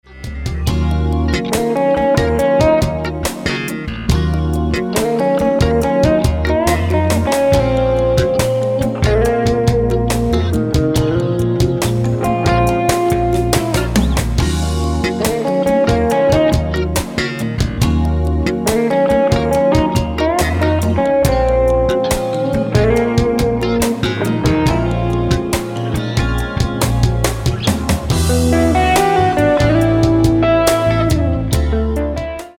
saxophones
guitars, keyboards and harmonicas
world-beat rhythms and sophisticated jazz arrangements
coastal smooth sound